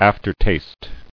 [af·ter·taste]